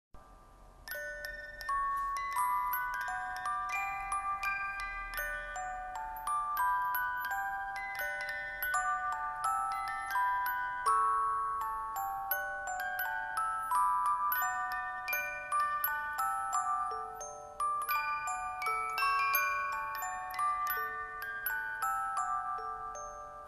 校歌のオルゴール
校歌が流れます♪